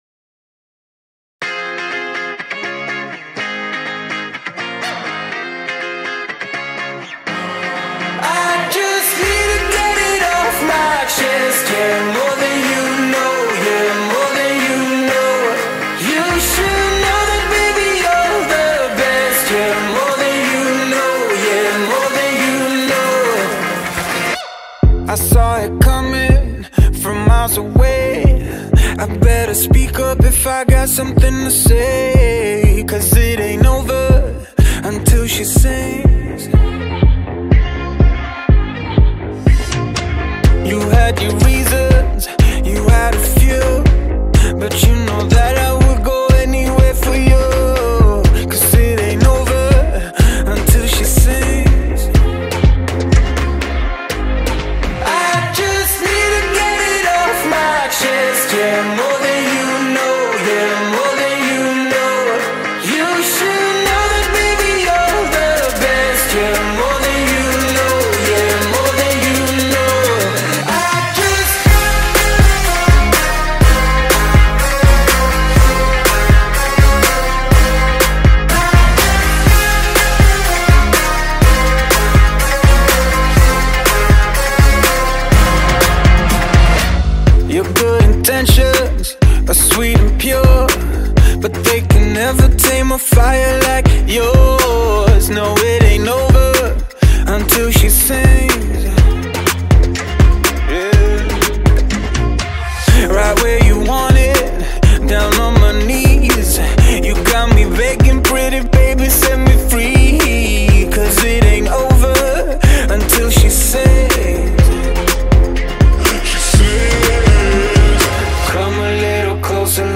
Carpeta: Baile internacional mp3